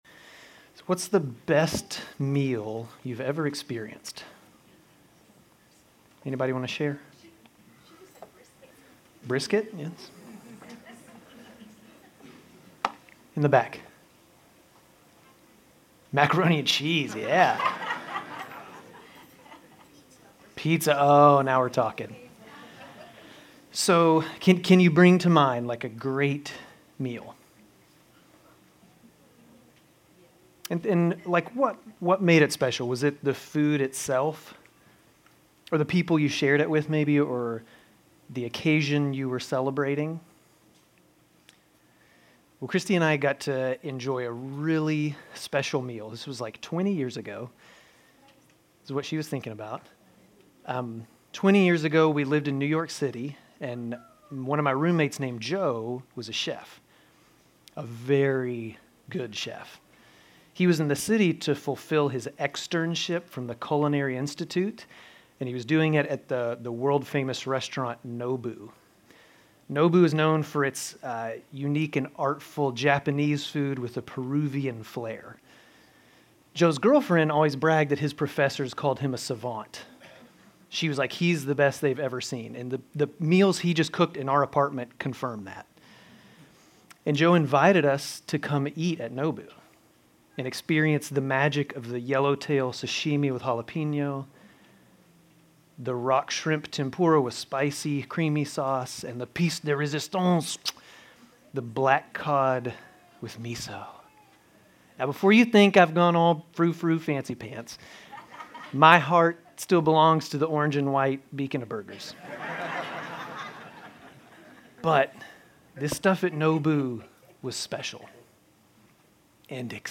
Grace Community Church Dover Campus Sermons 1_26 Sermon on the Mount Jan 27 2025 | 00:23:57 1x 00:00 / 00:23:57 Subscribe Share RSS Feed Share Link Embed